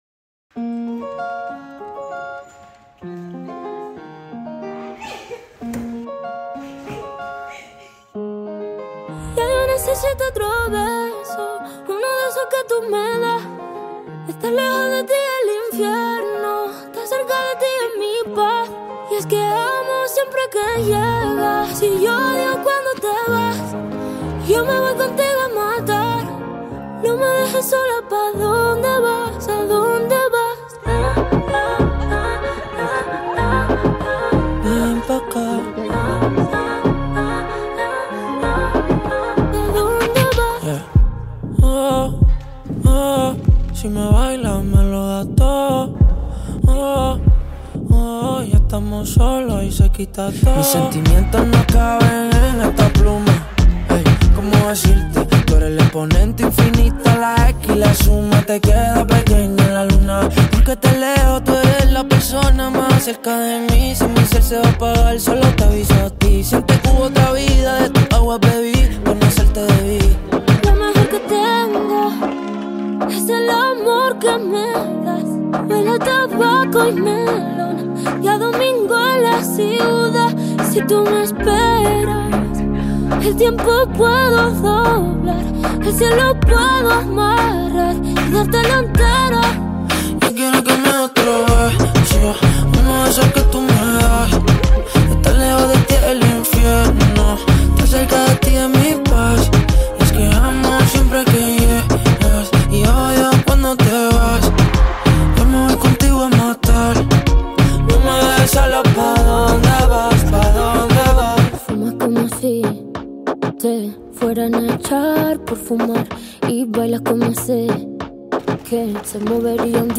Carpeta: Reggaeton y + mp3